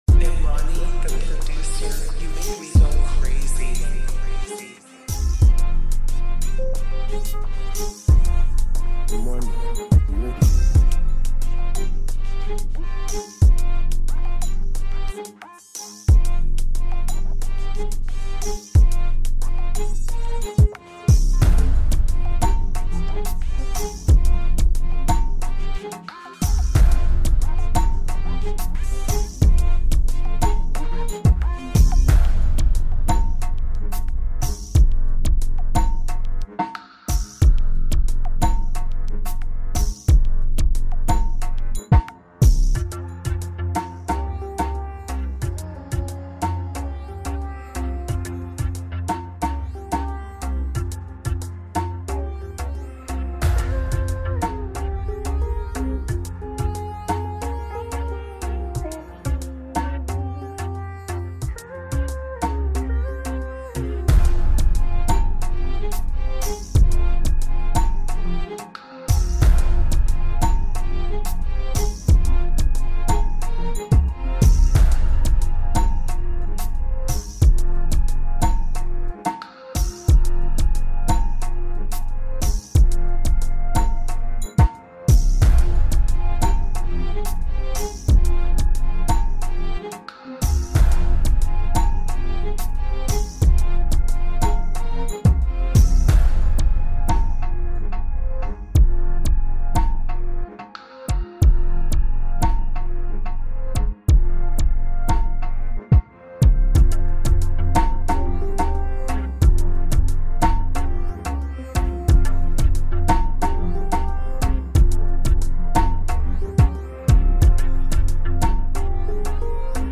Dancehall Instrumental